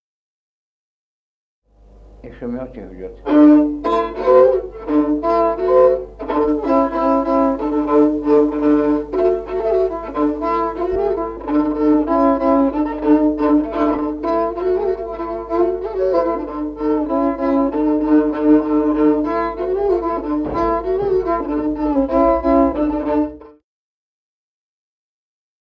Музыкальный фольклор села Мишковка «И шумёть, и гудёть», партия 2-й скрипки.